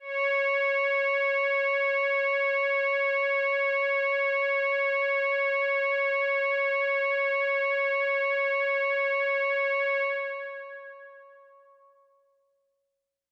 Roland Jupiter 4 Saxons Pad " Roland Jupiter 4 Saxons Pad C6（JP4 Saxons Pad85127 F3HI
标签： CSharp6 MIDI音符-85 罗兰木星-4 合成器 单票据 多重采样
声道立体声